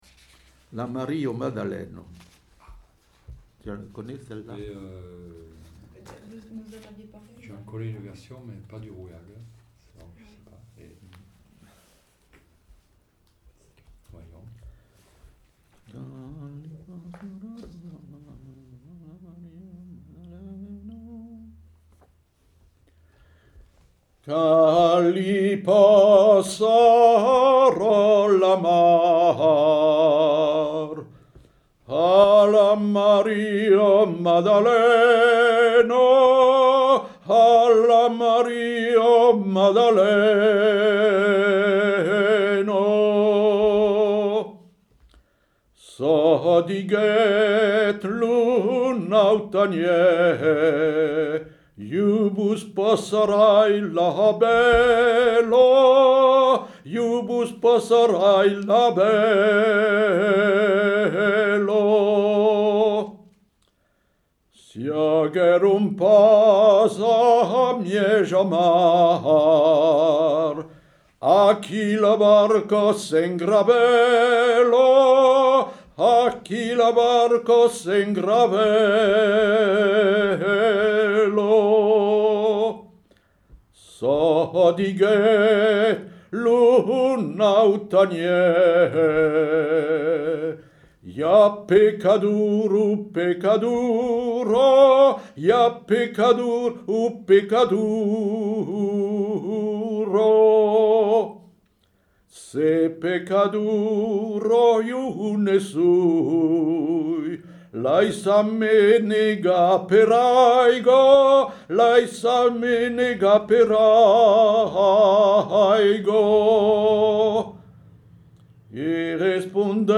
Aire culturelle : Rouergue
Lieu : Saint-Sauveur
Genre : chant
Effectif : 1
Type de voix : voix d'homme
Production du son : chanté